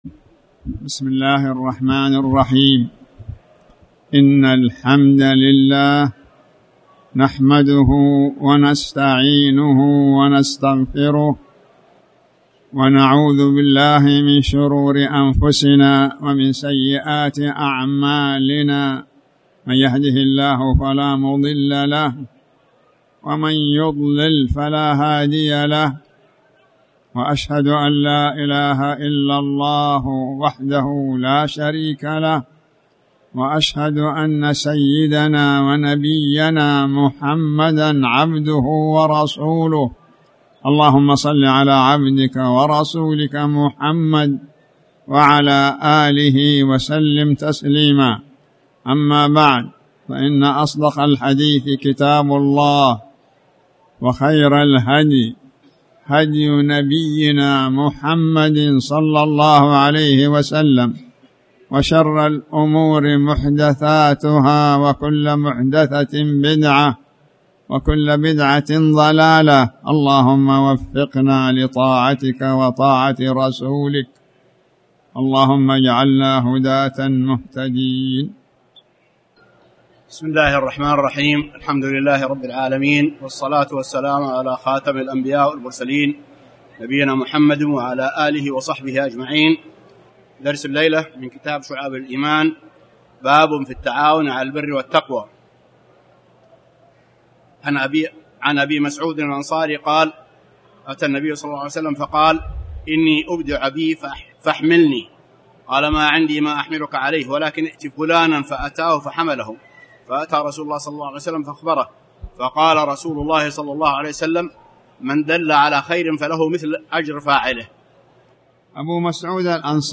تاريخ النشر ٤ محرم ١٤٣٩ هـ المكان: المسجد الحرام الشيخ